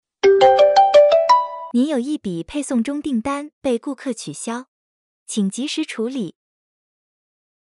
新提示音+语音 2-3.mp3